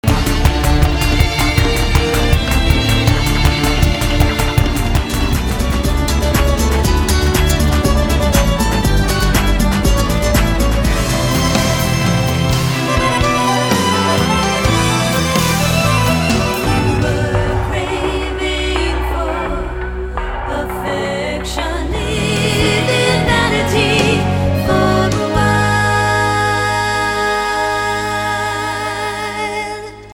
Take me back to the mix where the sampler was born:
gorgeous songs
an oddly-rendered torch song